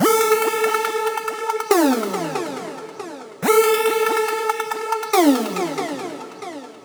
VEE Melody Kits 22 140 BPM Root A.wav